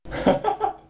Play Loof Laugh 2 - SoundBoardGuy
leiflaff2.mp3